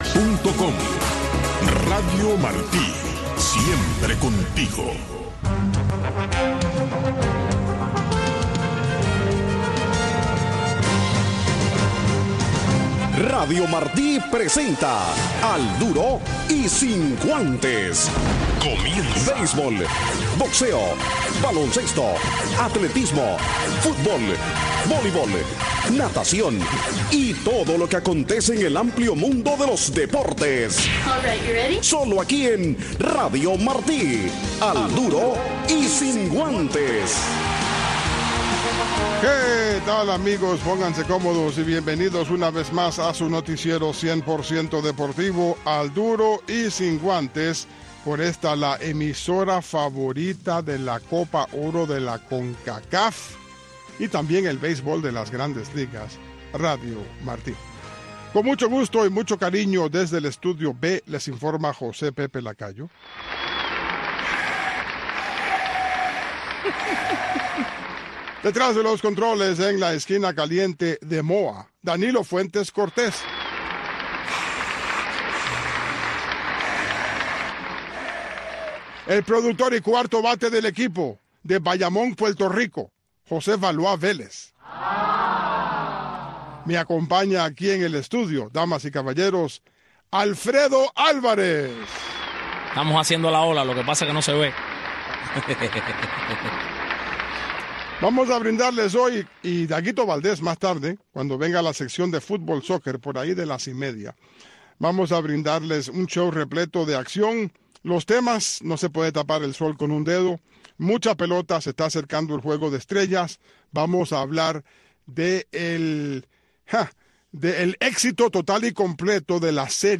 Un resumen deportivo en 60 minutos conducido